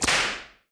whip3.wav